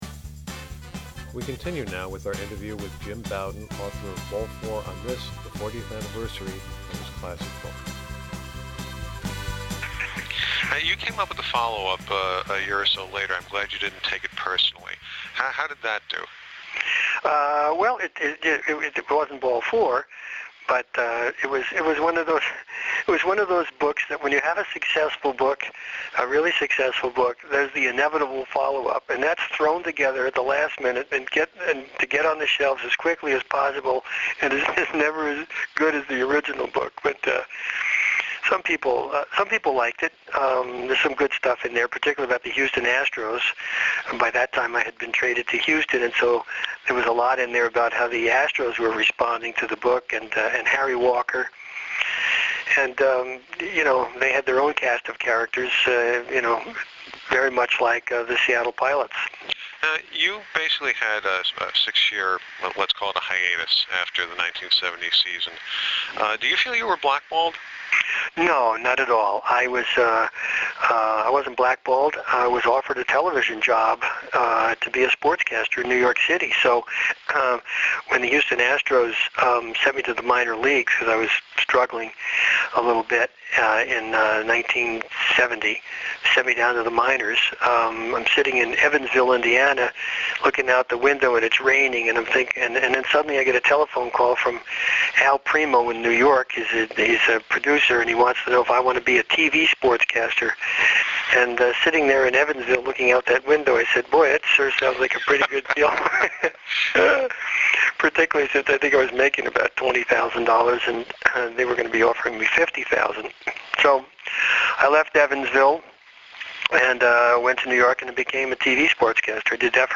Since the nature of the blog is to print the most recent entry first, I'm presenting the three-part interview with Jim Bouton in reverse order.